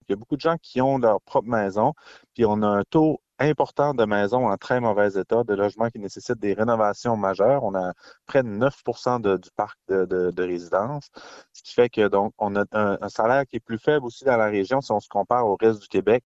Le président de la Table des préfets, Mathieu Lapointe, affirme avoir été très surpris de cette coupure annoncée sans tambour ni trompette dans le budget provincial :